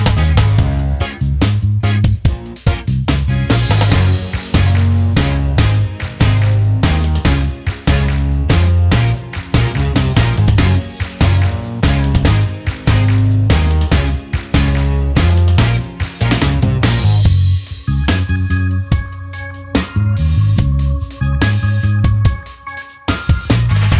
Minus Main Guitar Pop (2010s) 3:45 Buy £1.50